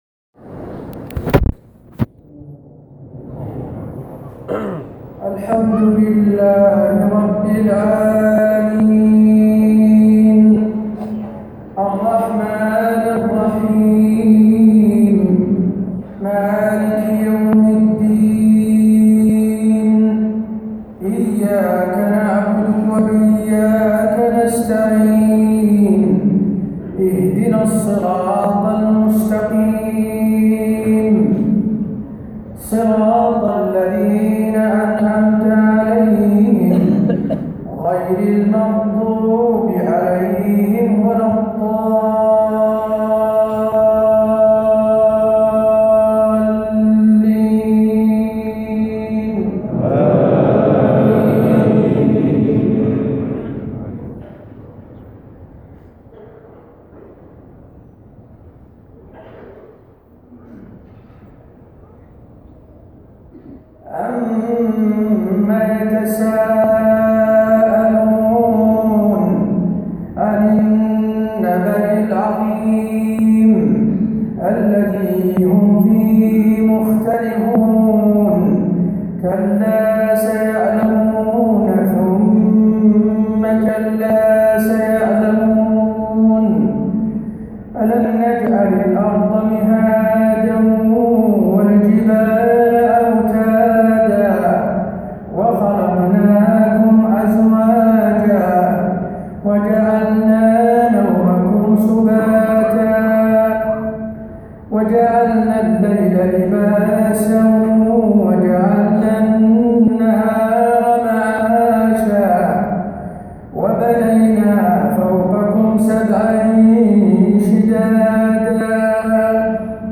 صلاة العشاء 19 رمضان 1437هـ سورة النبأ > 1437 🕌 > الفروض - تلاوات الحرمين